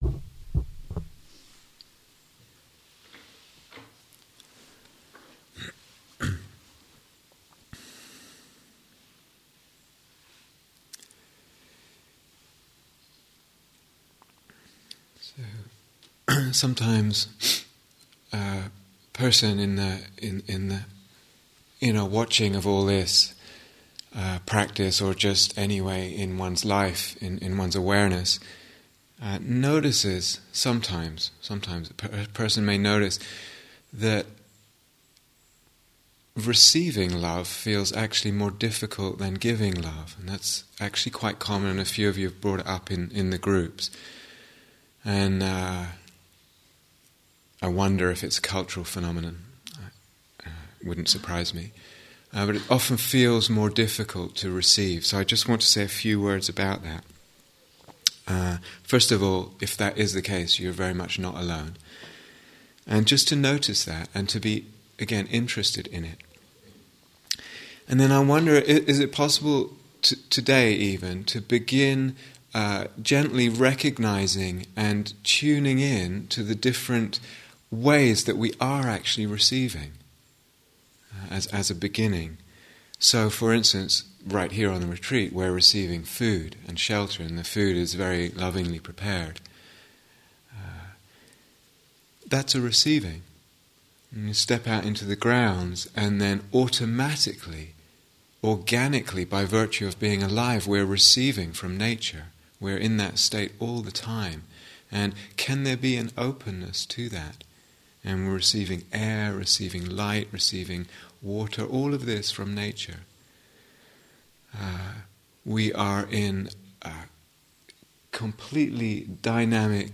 Mettā Instructions and Guided Meditation 3